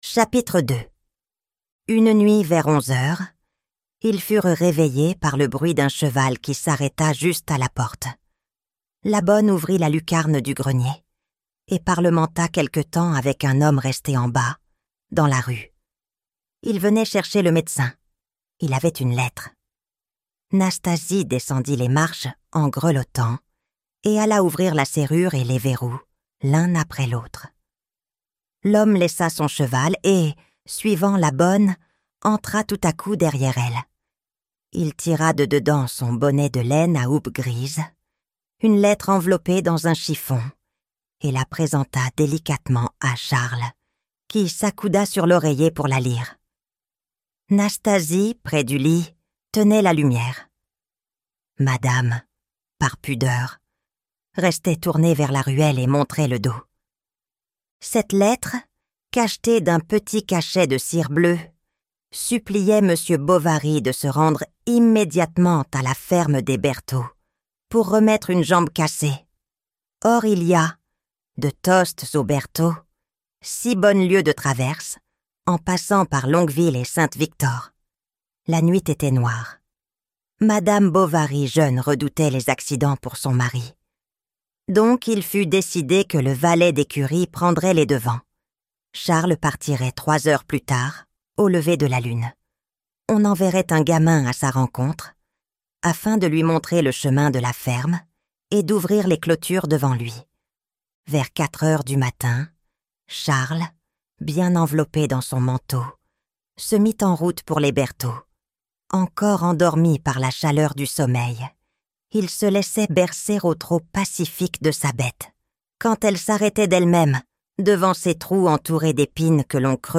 Madame Bovary - Livre Audio